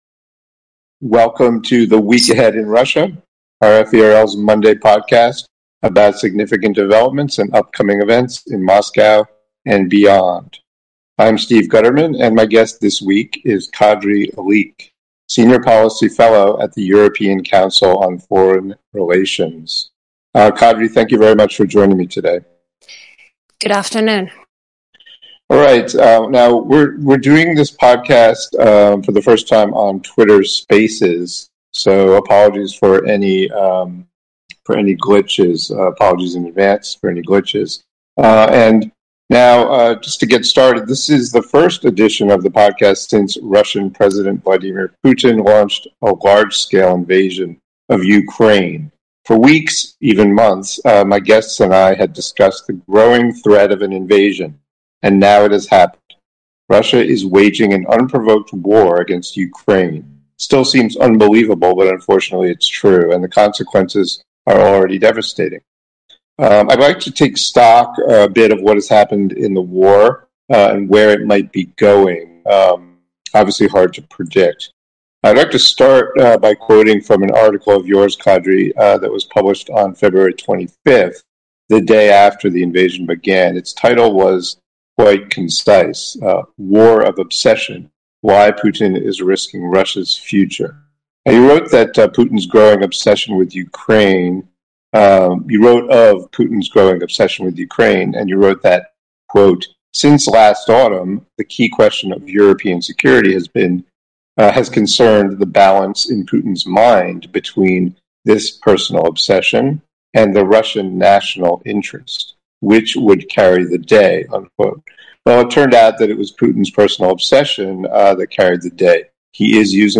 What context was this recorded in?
a special edition on Twitter Spaces